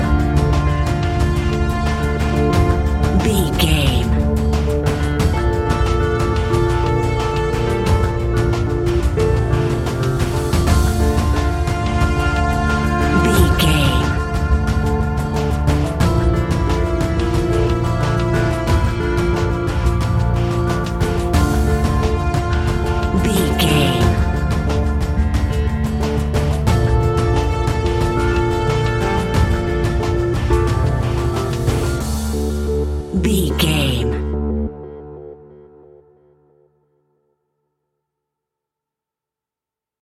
In-crescendo
Aeolian/Minor
ominous
dark
eerie
synthesiser
drums
percussion
electronic music
electronic instrumentals